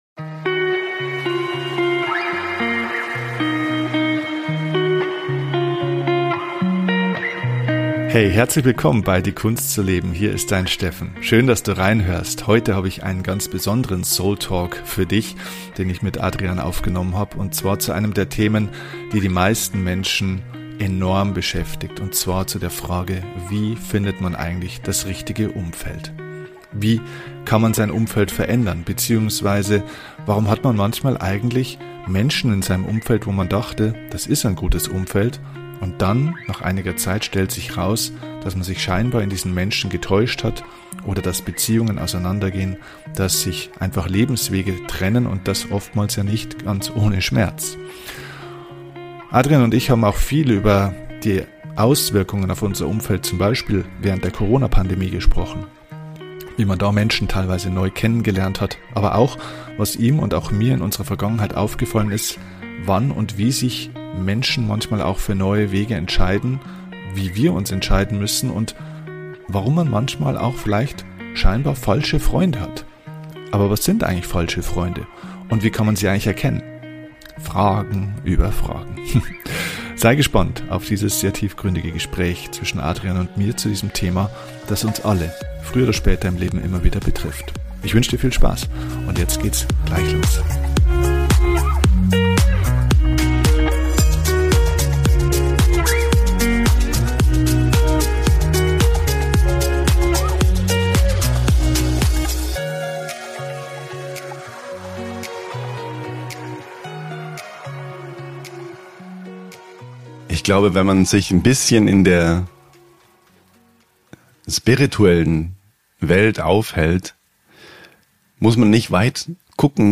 Wie immer bei diesem Format: es gibt kein Skript, nur zwei Freunde die miteinander sprechen.